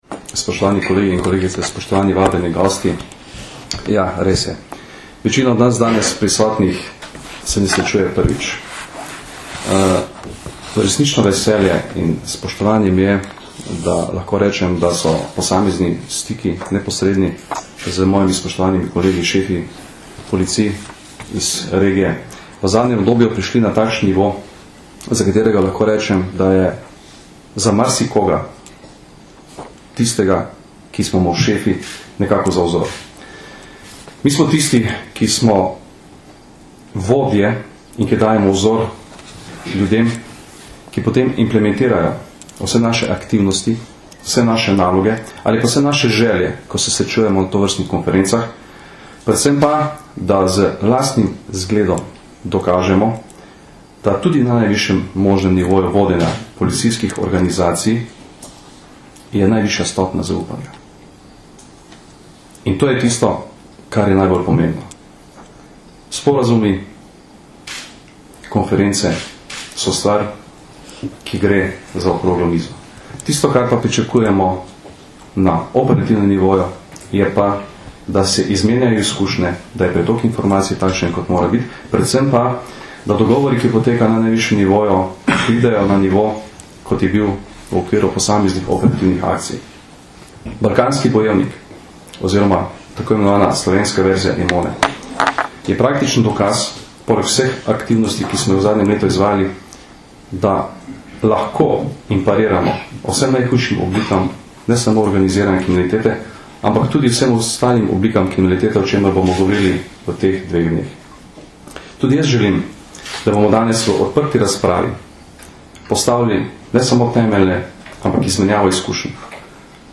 Na Brdu pri Kranju se je danes, 19. maja 2011, dopoldne s pozdravnim nagovorom generalnega direktorja policije Janka Gorška začelo dvodnevno srečanje šefov policij Zahodnega Balkana.
Zvočni posnetek nagovora Janka Gorska (mp3)